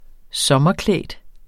Udtale [ -ˌklεˀd ]